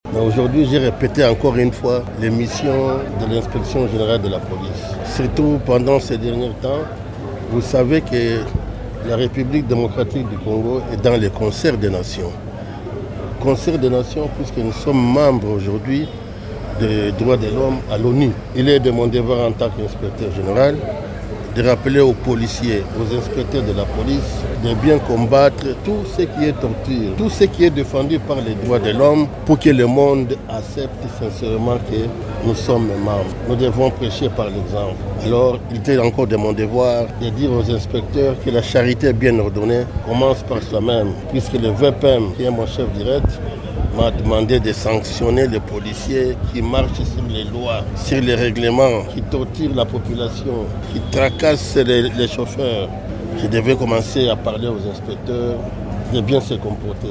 Il instruit tous ses éléments à ne pas « noircir l'image de la RDC à la face du monde ». Cet ordre a été donné lors d'une parade lundi 21 octobre à l'esplanade de l'administration centrale de l'IGPNC sur l'avenue de la Juste à Gombe, à Kinshasa.